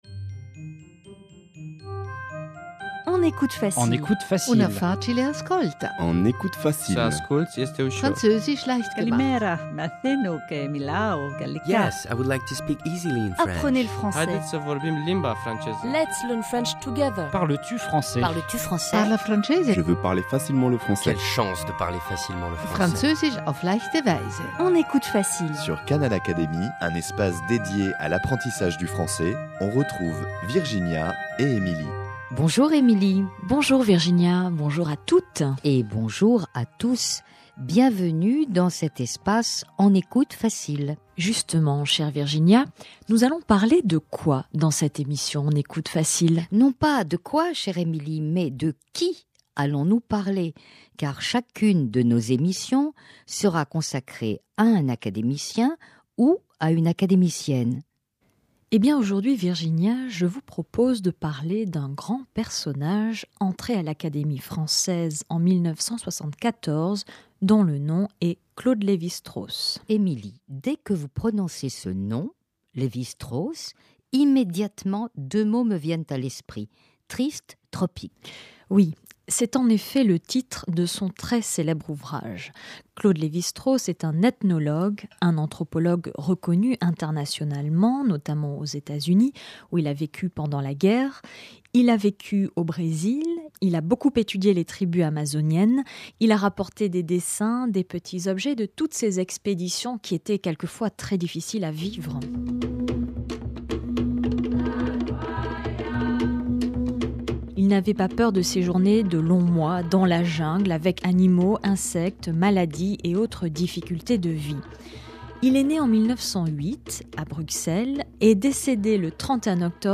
Pour suivre cette présentation, nous vous invitons à écouter tout en lisant la transcription de ce dialogue à la fois ludique et instructif :